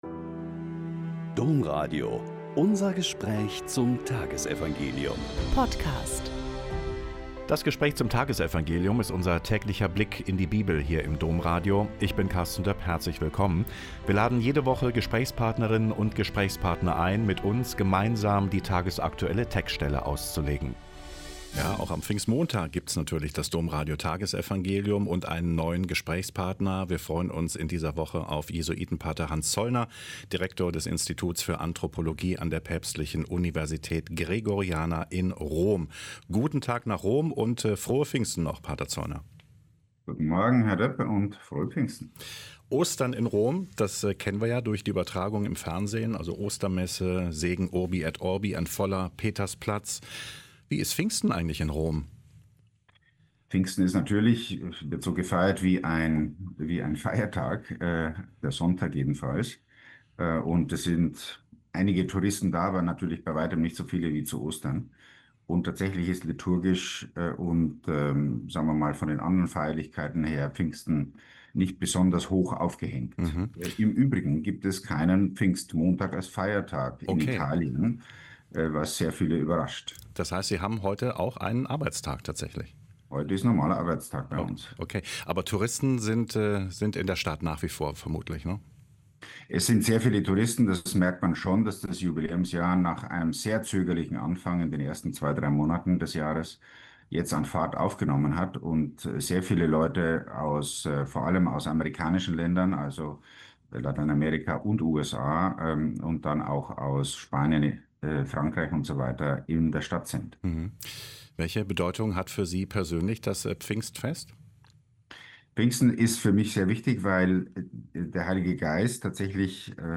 Joh 15,26 - 16,3.12-15 - Gespräch mit Pater Hans Zollner SJ